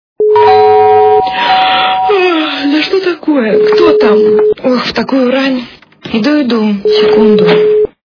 » Звуки » Будильники » Звонок с утра - Кто-там в такую рань, иду, иду...
При прослушивании Звонок с утра - Кто-там в такую рань, иду, иду... качество понижено и присутствуют гудки.